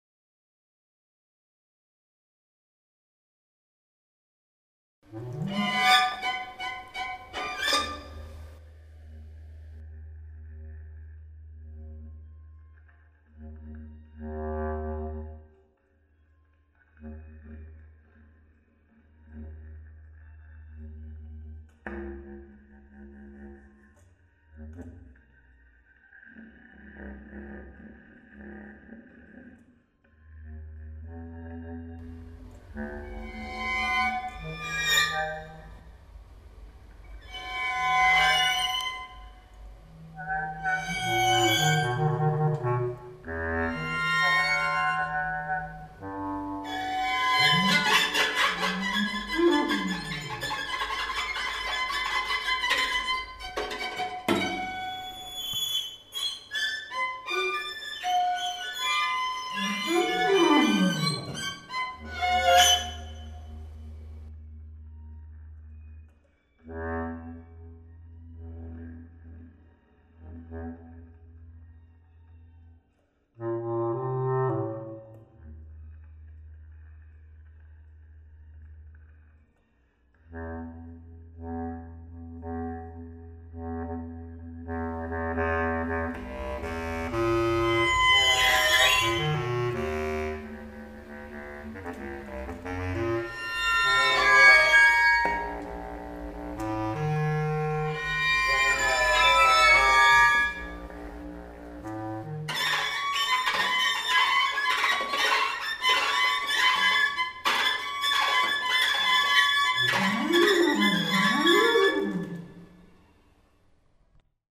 пьеса для струнного квартета и бас-кларнета.